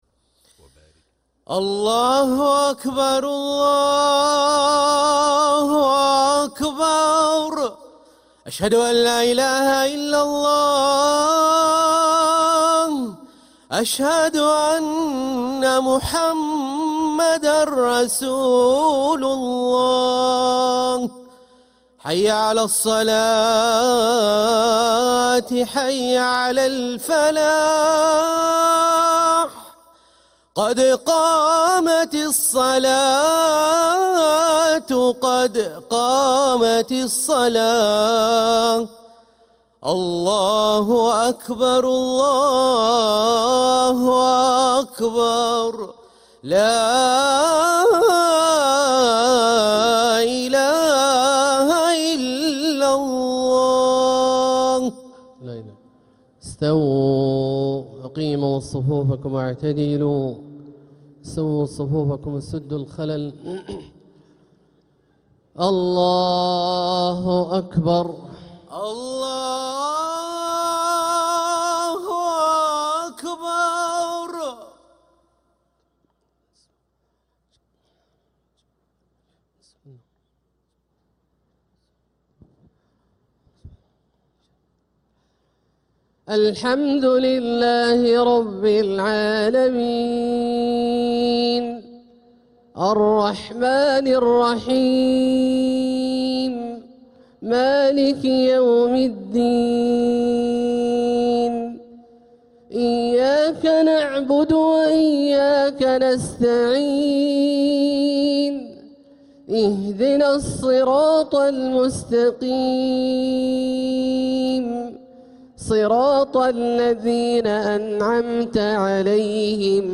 Haramain Salaah Recordings: Makkah Maghrib - 15th January 2026